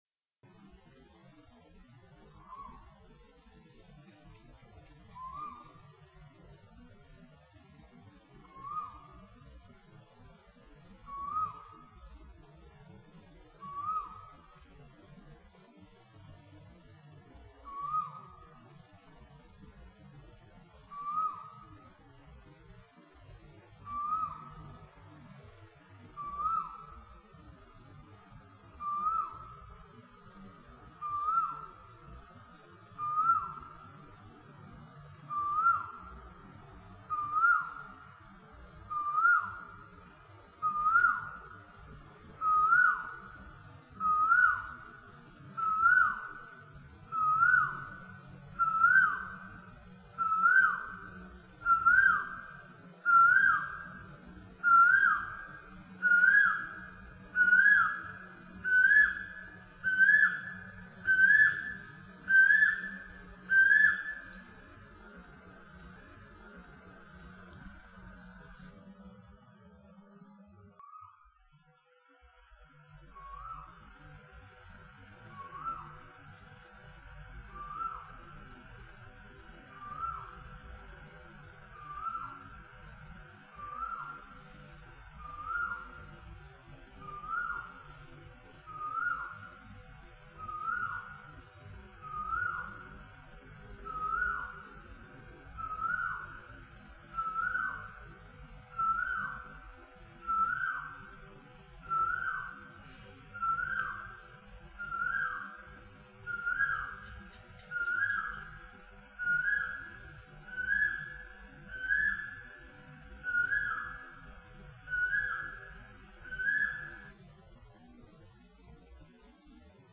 common-koel-3am.mp3